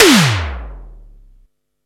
SIMMONS SDS7 8.wav